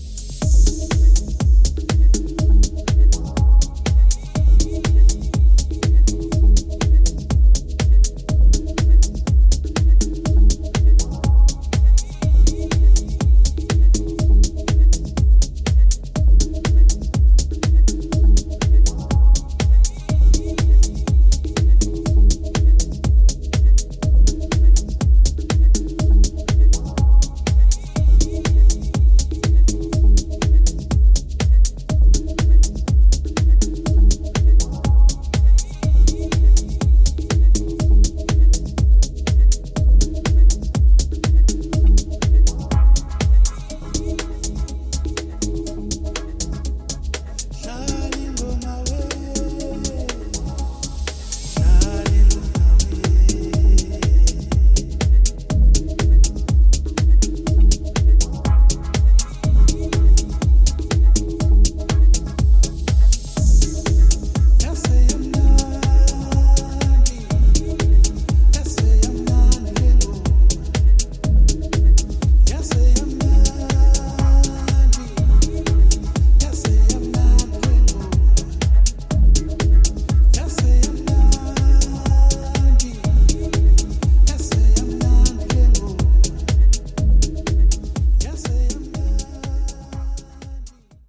ソリッドなトラックにウォームな奥行きを与えています！